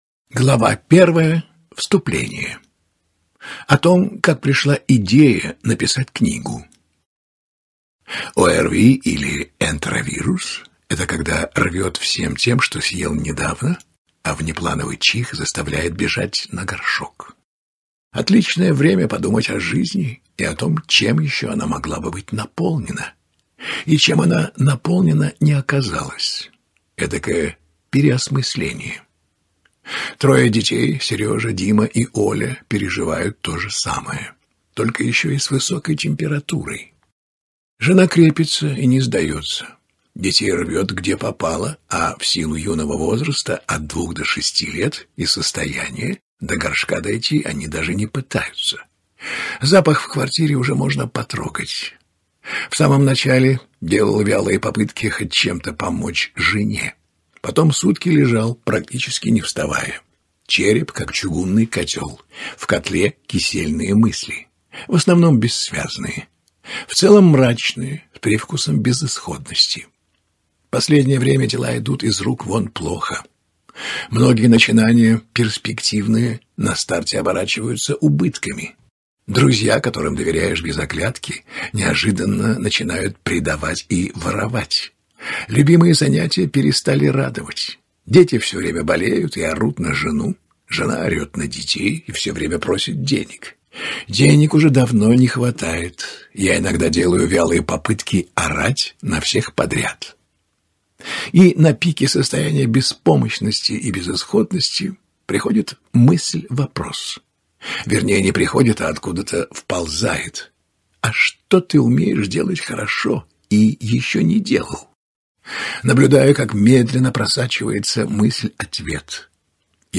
Аудиокнига О чем говорят мужские чувства | Библиотека аудиокниг